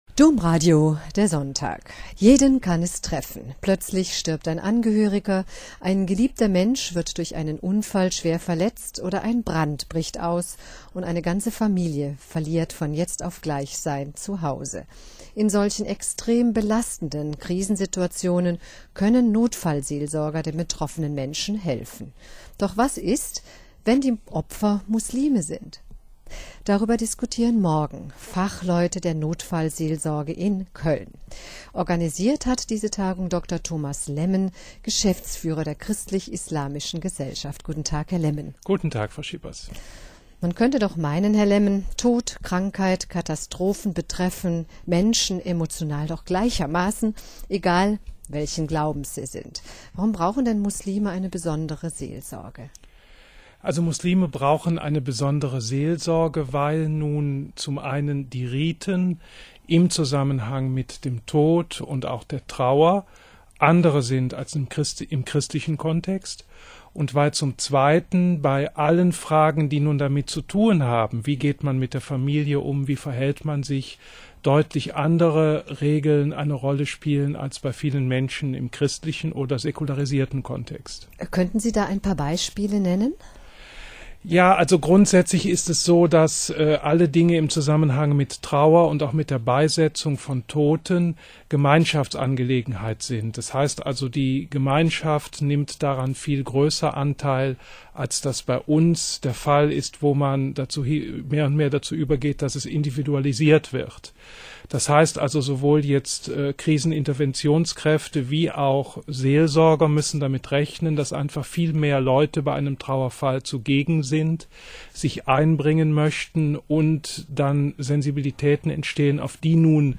Audio: Interview